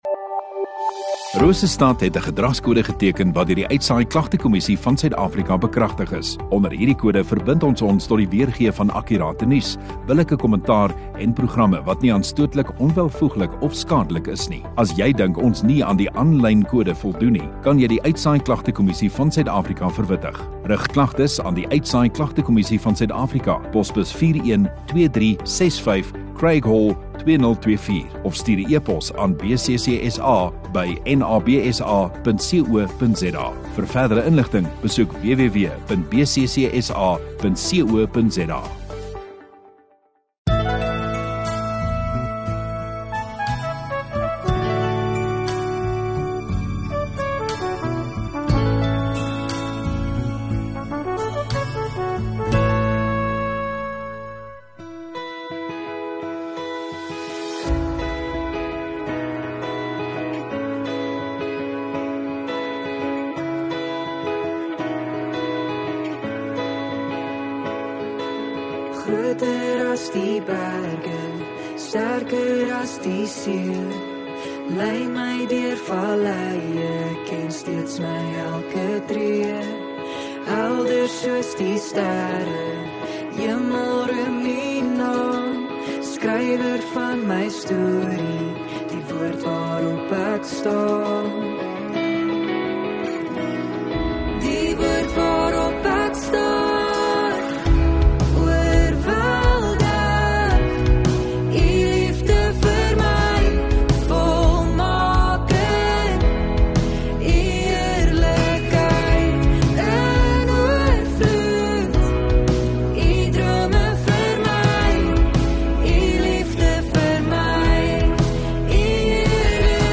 22 Nov Vrydag Oggenddiens